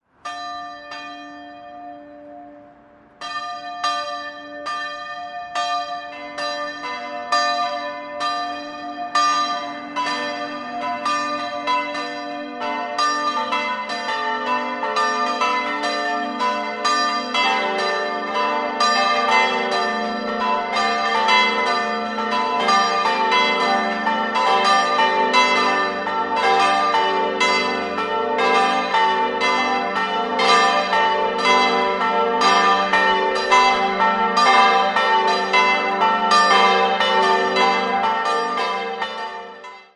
4-stimmiges ausgefülltes G-Dur-Geläute: g'-a'-h'-d'' Die Glocken wurden 1964 von Grassmayr in Innsbruck gegossen.